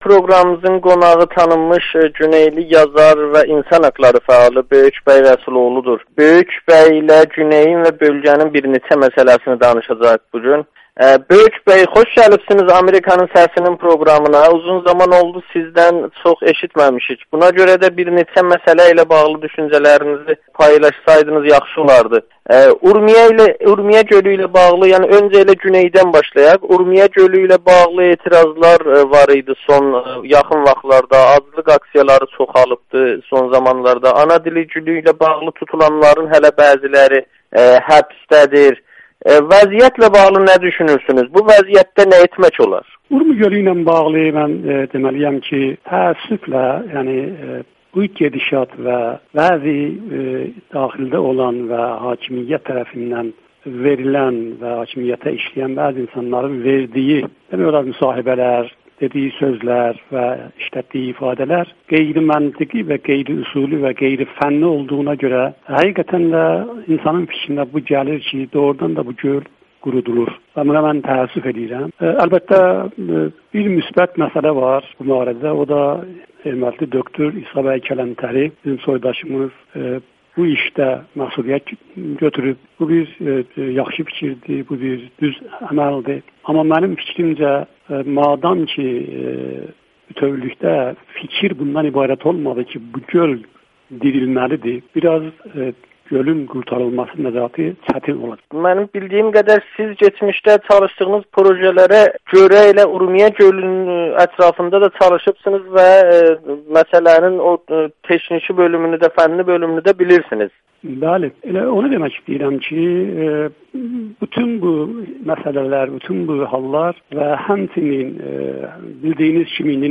müsahibə